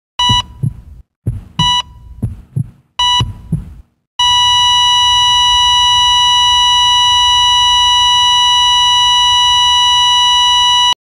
Heartbeat Flatline Sound Effect Free Download
Heartbeat Flatline